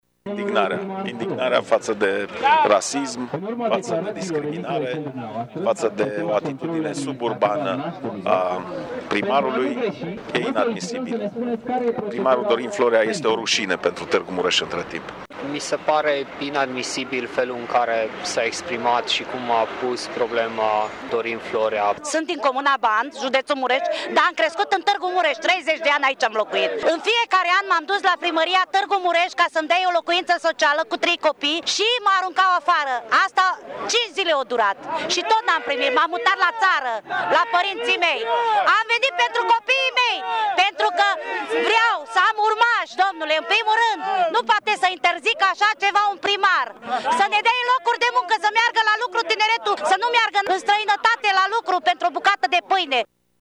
Participanții la protest provin din toate păturile societății și sunt uniți prin lupta împotriva rasismului și nedreptăților: